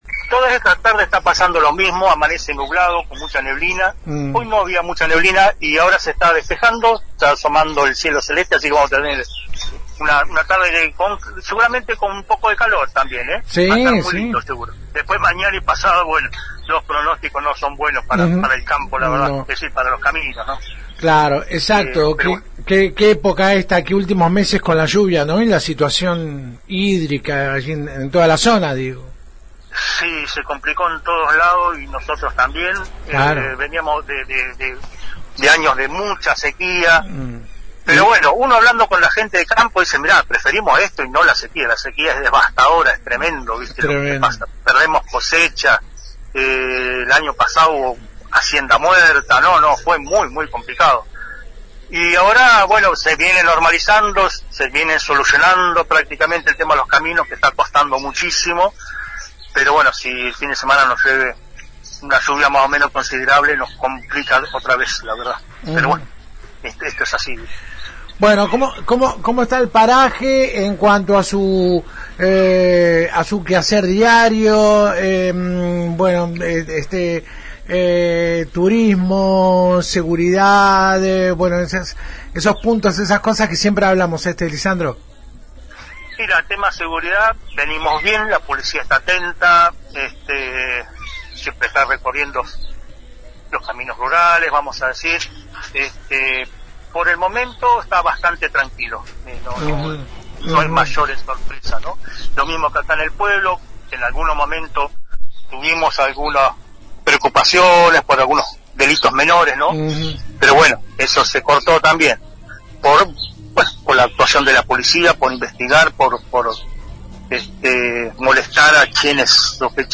«Nos encontramos muy bien y creciendo de a poco con una apuesta fuerte al turismo rural y demás proyectos que hacen a nuestro querido paraje», sostuvo ésta mañana Lisandro Paggi, delegado municipal, a FM Alpha.